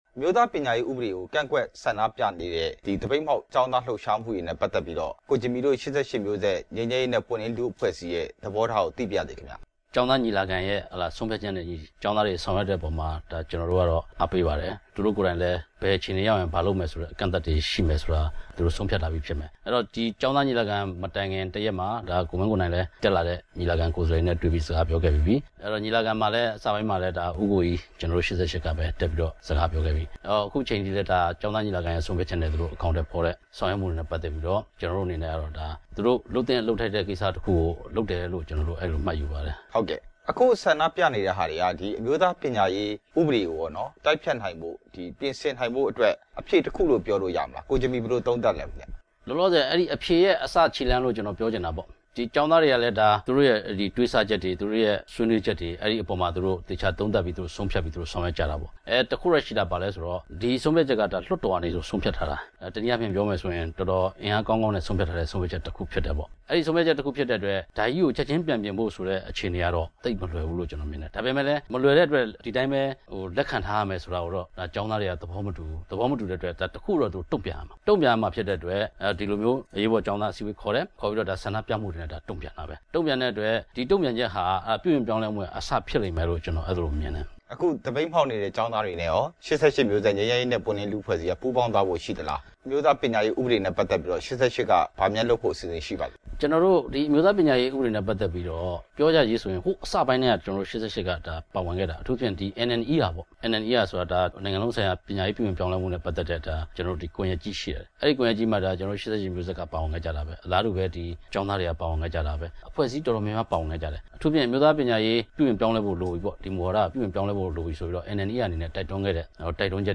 ပညာရေးဥပဒေကို ကန့်ကွက် တဲ့ ကျောင်းသားတွေနဲ့ အစိုးရတွေ့ဖို့ လိုအပ်ကြောင်း မေးမြန်းချက်